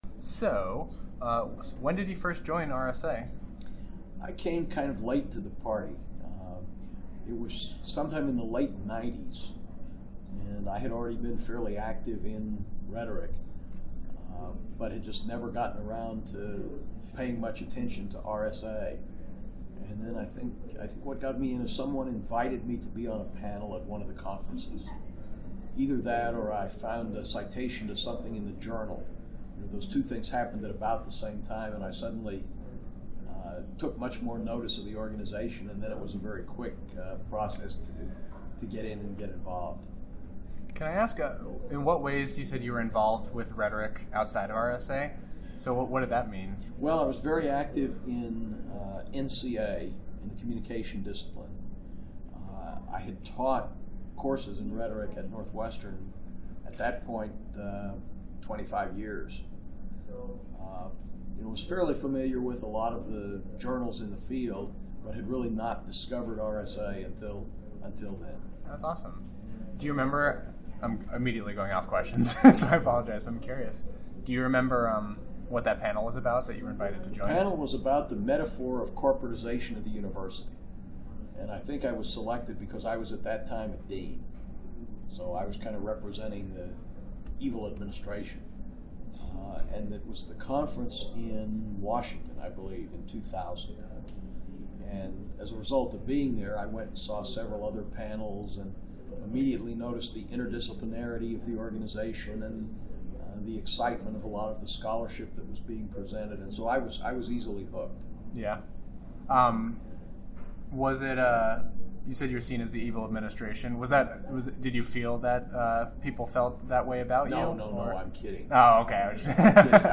Archival materials from the Rhetoric Society of America's Oral History Initiative.
Oral History
Location 2018 RSA Conference in Minneapolis, Minnesota